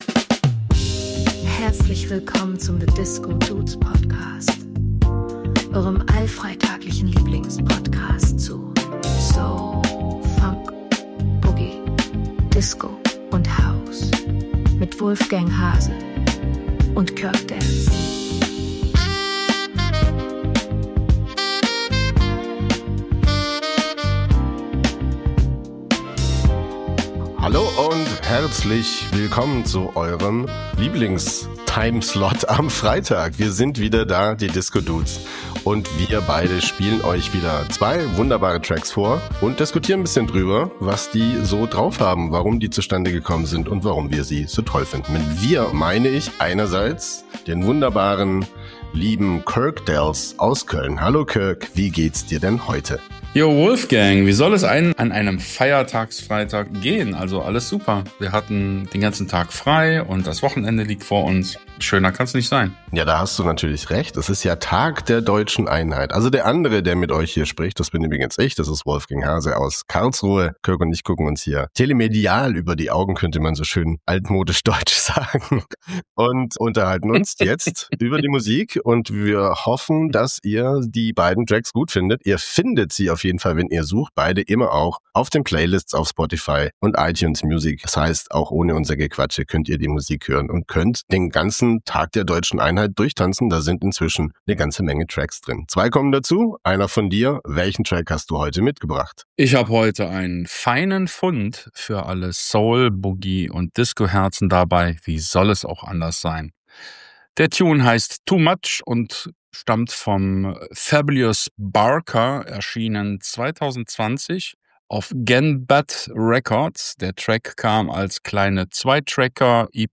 Nu Disco Nugget 🪩 meets 🪩 easy chillin' 80s Crooner